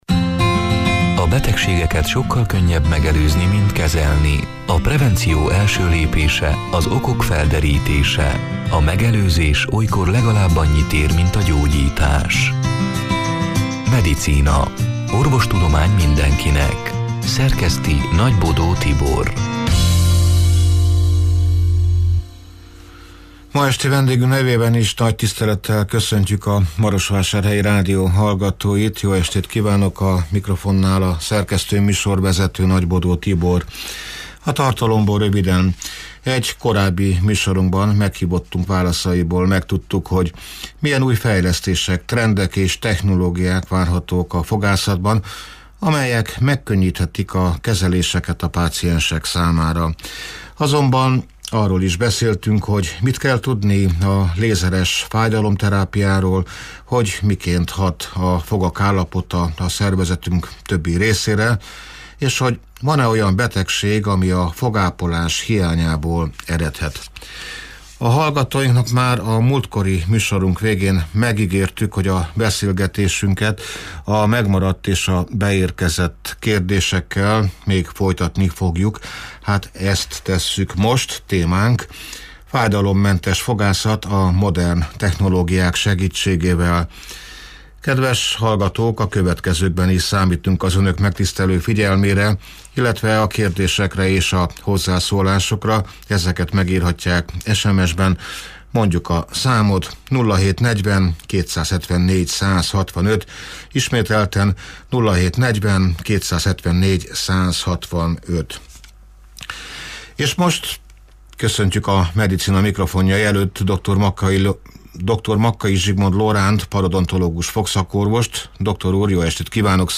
(elhangzott: 2025. január 22-én, szerdán este nyolc órától élőben)